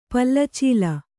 ♪ palla cīla